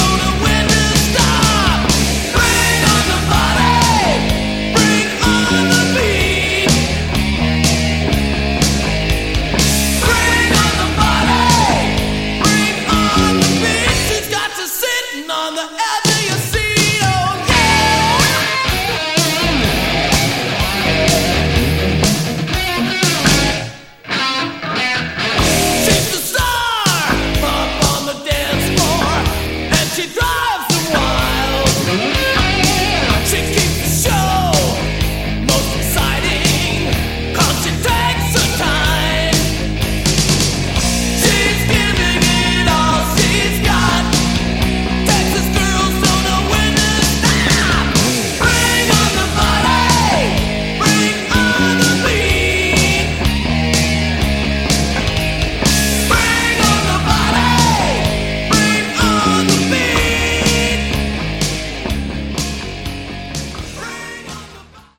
Category: Hard Rock
vocals, acoustic guitar
keyboards, guitar
drums, backing vocals
The rest is demo sound quality.